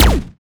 Sci-Fi Effects
weapon_laser_008.wav